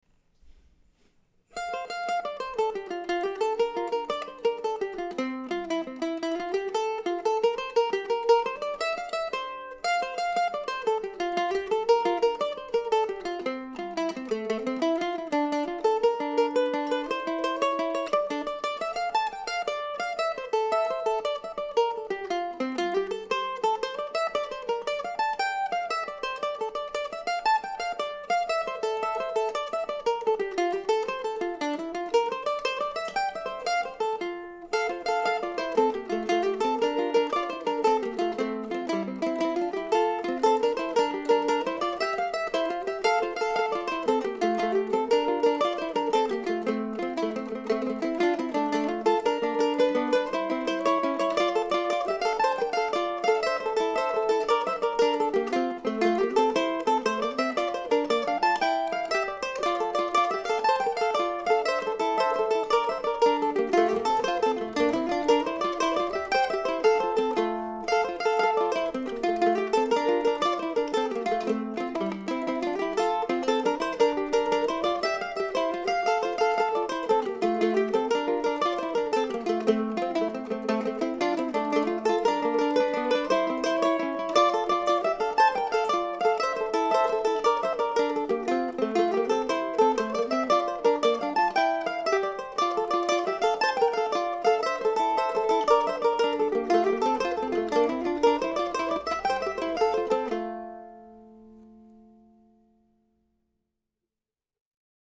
This week's tune (2017, week 32) is a nice jig in F, presented as a duo for two mandolins.